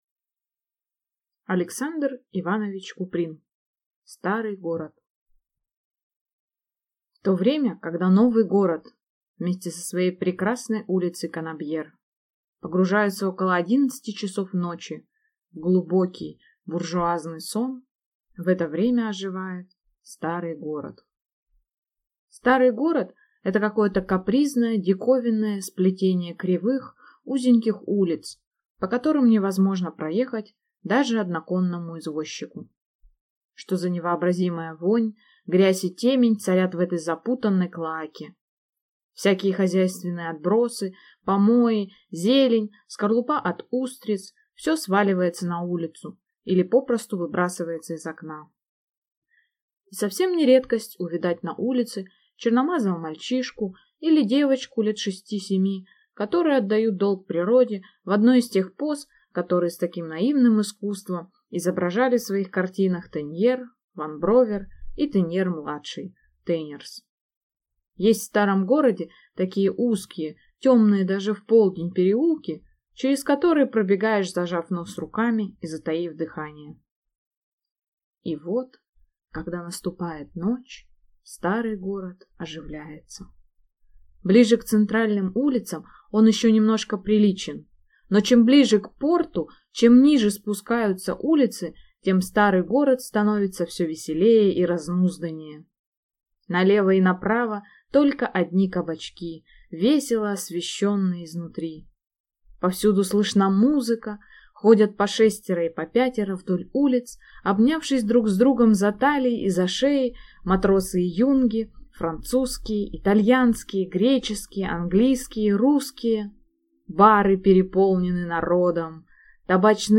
Aудиокнига Старый город Автор Александр Куприн Читает аудиокнигу